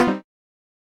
Techmino/media/effect/chiptune/back.ogg at 12ea2d76beb4806fb421e9c119cdef802489aaf3
back.ogg